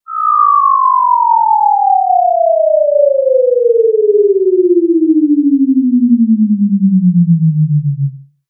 CartoonGamesSoundEffects
Falling_v3_wav.wav